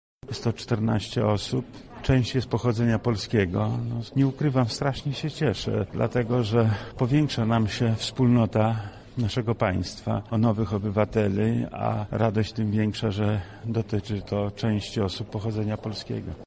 — mówi Wojewoda Lubelski Lech Sprawka.